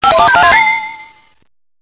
リールが回転した瞬間に聞こえる2種類の予兆サウンド！
ベル予兆音
「ベルリラリラ」と鳴って、ベルがはずれたら・・・